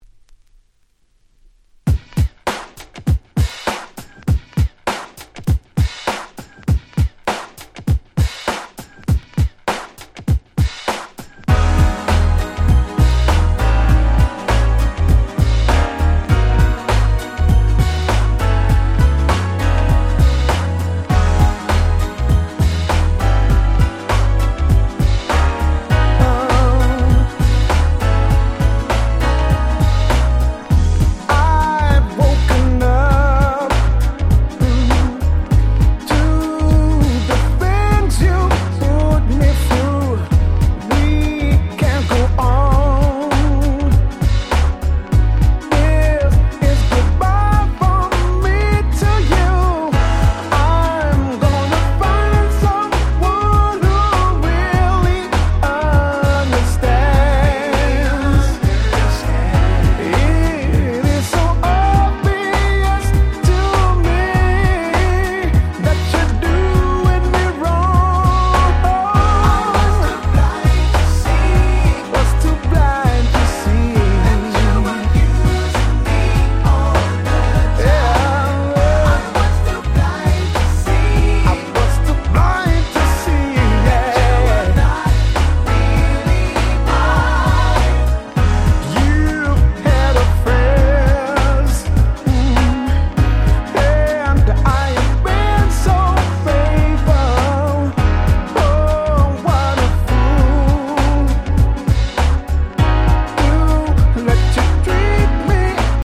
Super Nice UK R&B !!